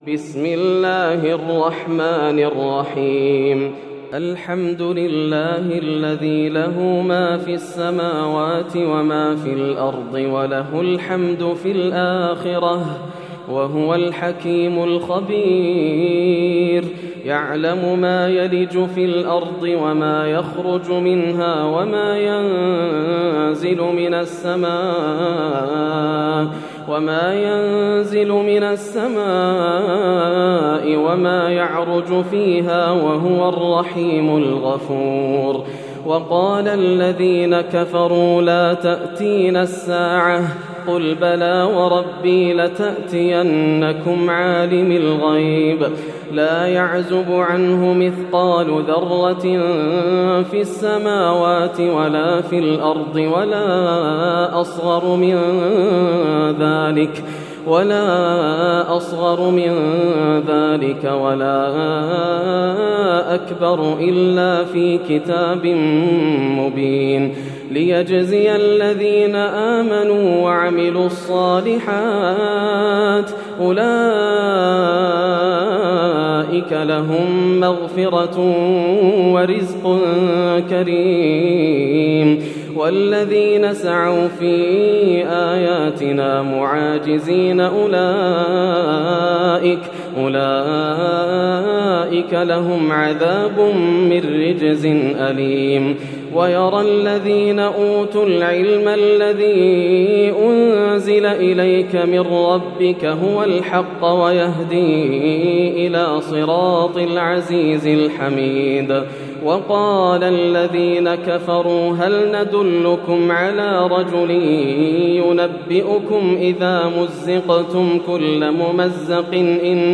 سورة سبأ > السور المكتملة > رمضان 1431هـ > التراويح - تلاوات ياسر الدوسري